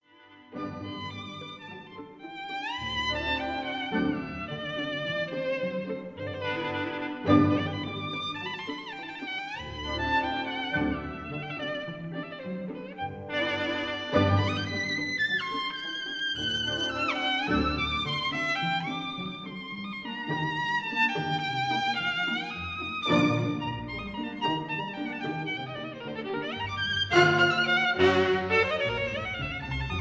Scherzando (Allegro molto) and 3.